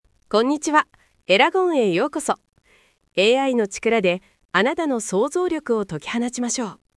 キャラクター設定と演技指定による多彩な音声生成：
-c "プロフェッショナルな女性ナレーター" -d "落ち着いた声で" |
narration-professional.mp3